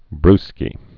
(brskē)